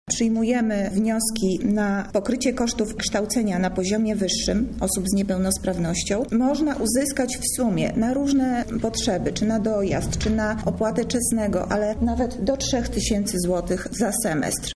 – dodaje Lipińska